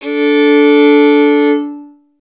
STR FIDDL 0C.wav